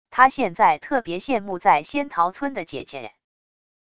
他现在特别羡慕在仙桃村的姐姐wav音频生成系统WAV Audio Player